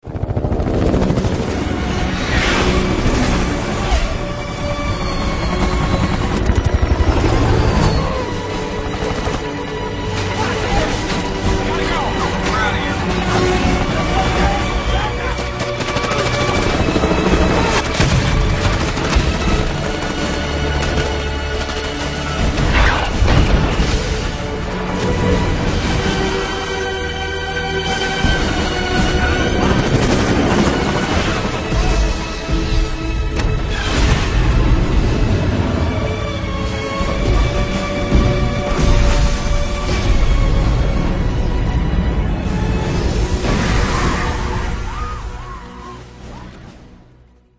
choppers1.mp3